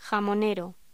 Locución: Jamonero
voz